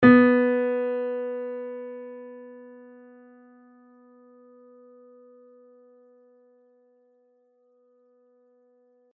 samples / piano / B3.mp3
B3.mp3